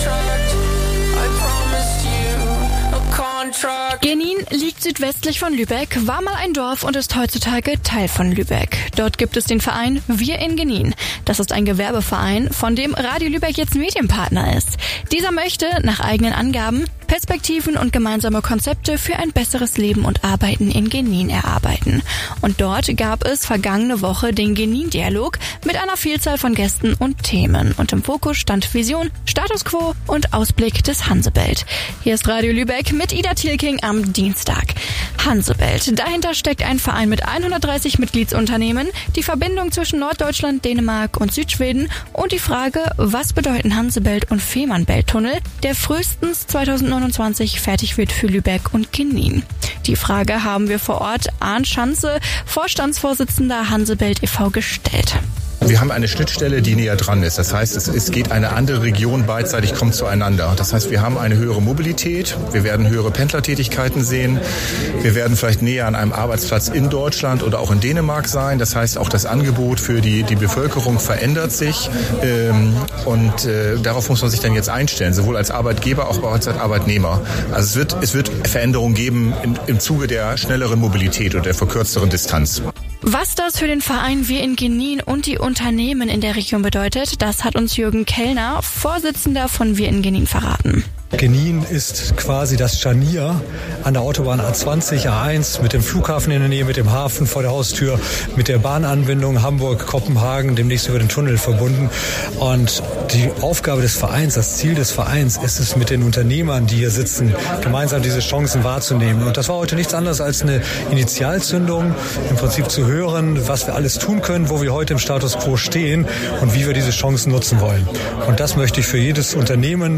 Sendung Radio Lübeck
radio-luebeck-bei-wiringenin.mp3